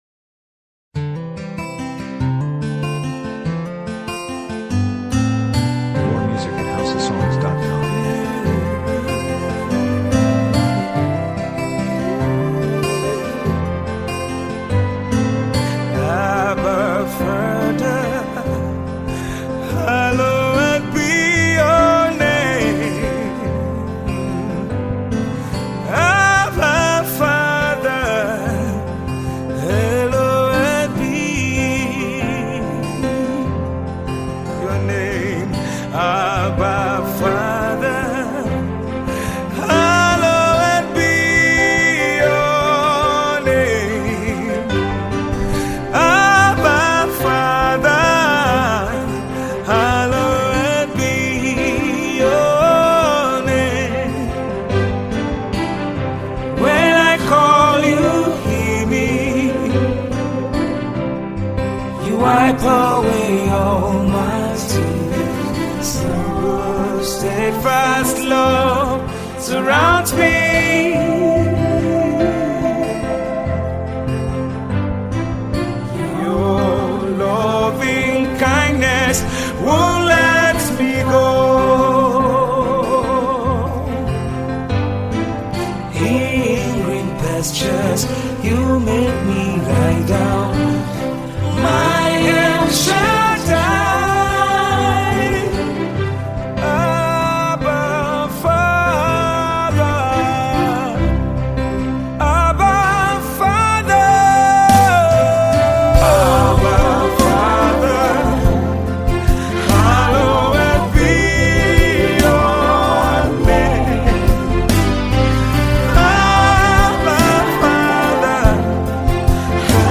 encouraging, uplifts the spirit and soul
Tiv Song